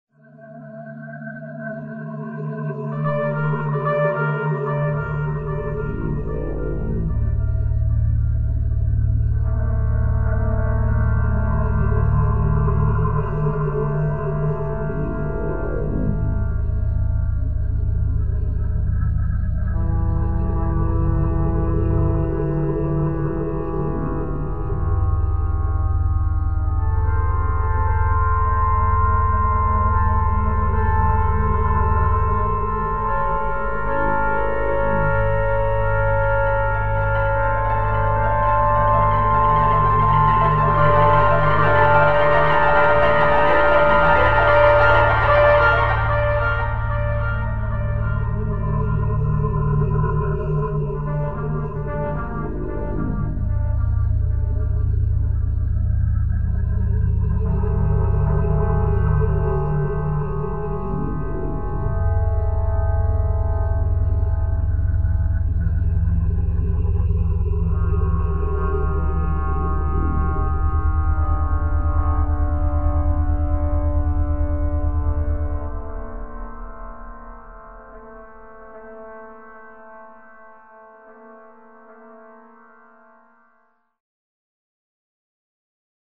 Страшная музыка (без авторских прав)